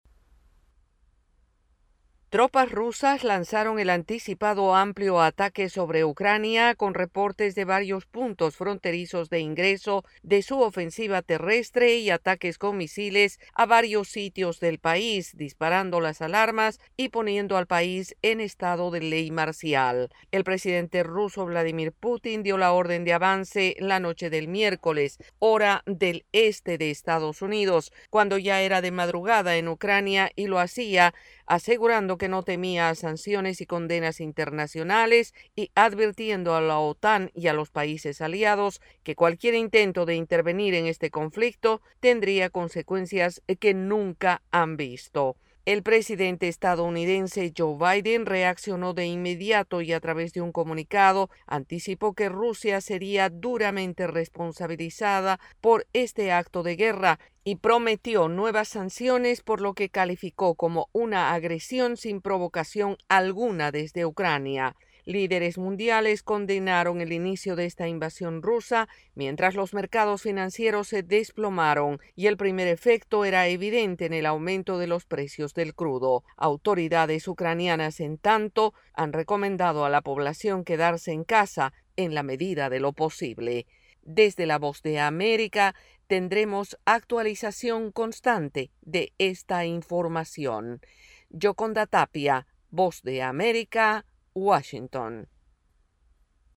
AudioNoticias
informa desde la Voz de América en Washington DC.